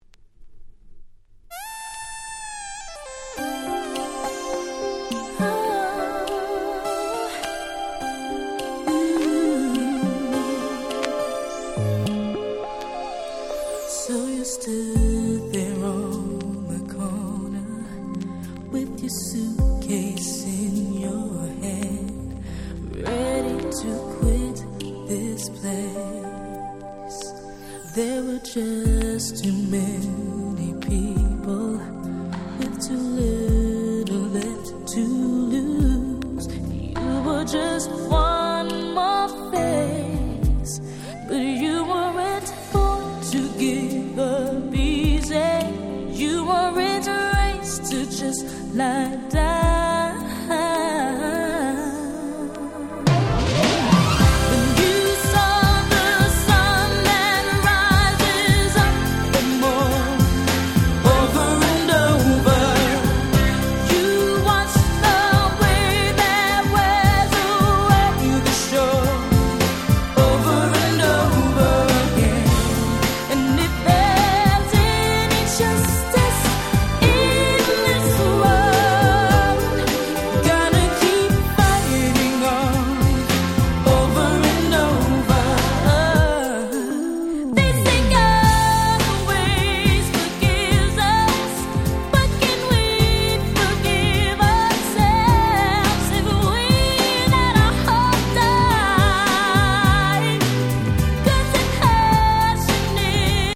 96' Very Nice R&B !!
壮大なDanceチューンで大変テンションが上がります！！